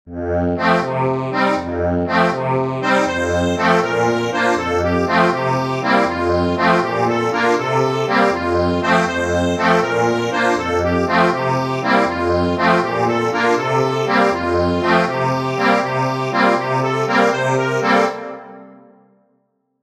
Instrument: accordion
Easy accordion arrangement plus lyrics.